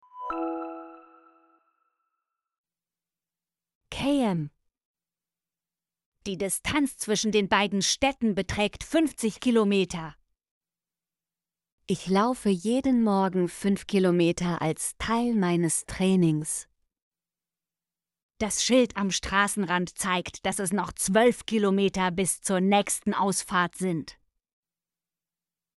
km - Example Sentences & Pronunciation, German Frequency List